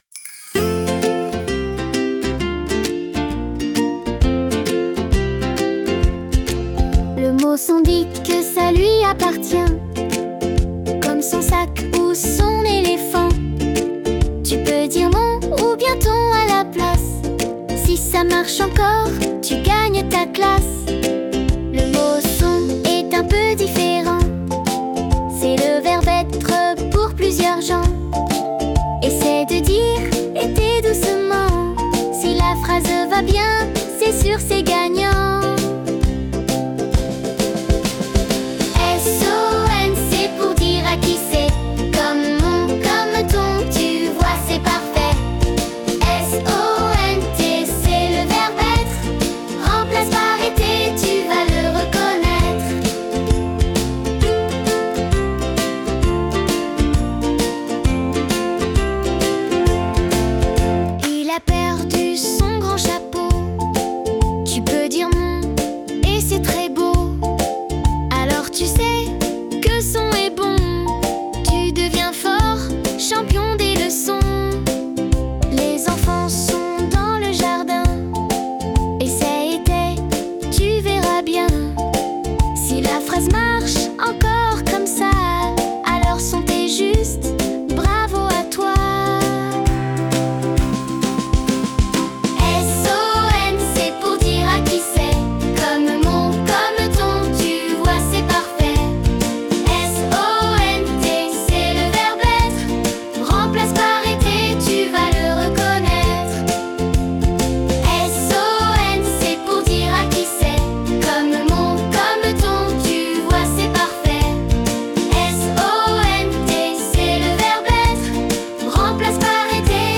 Chanson : Son ou Sont